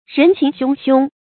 人情汹汹 rén qíng xiōng xiōng
人情汹汹发音